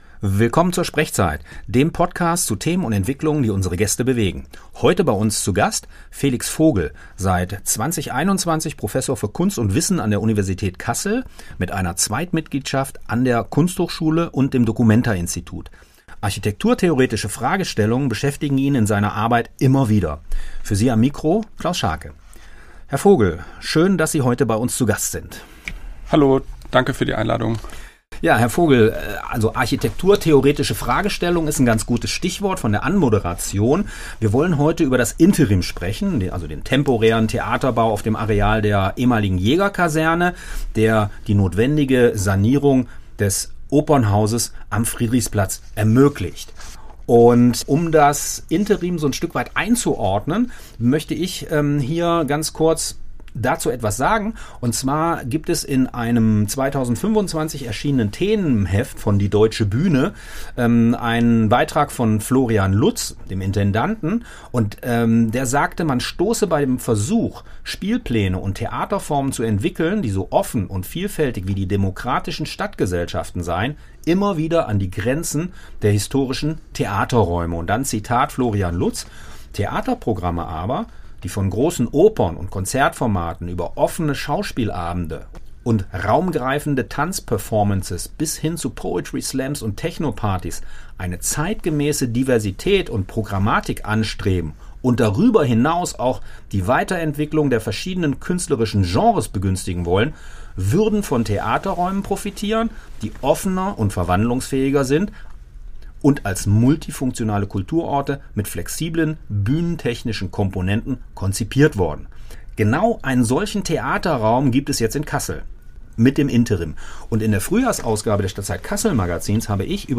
Diese SprechZeit-Folge wurde 19. März 2026 beim Freien Radio Kassel aufgezeichnet.